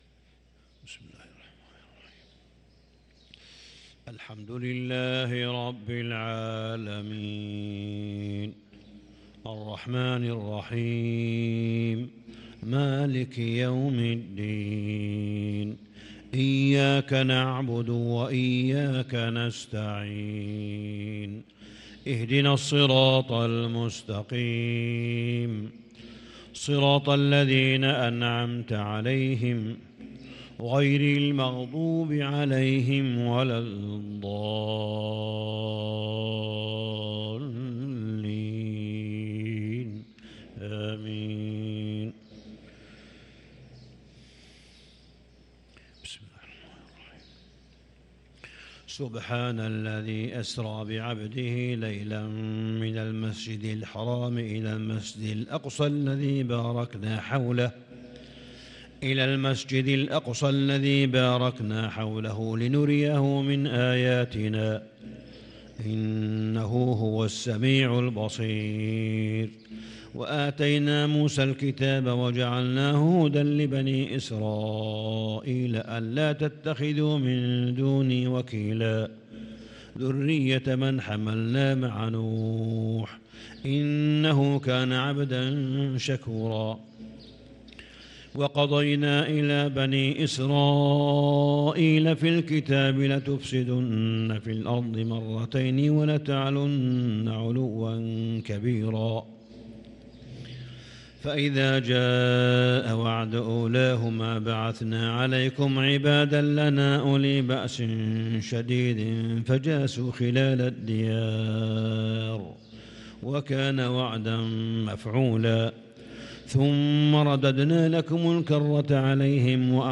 فجر الثلاثاء 4 رمضان 1443هـ فواتح سورة الإسراء | Fajr prayer from Surat Al-Israa 5-4-2022 > 1443 🕋 > الفروض - تلاوات الحرمين